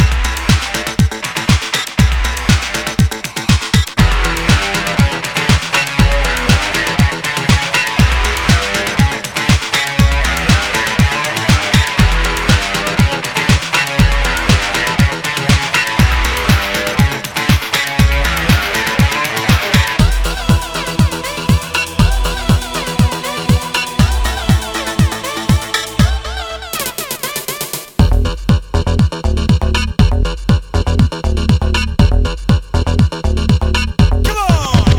Жанр: Рок / Танцевальные / Альтернатива / Техно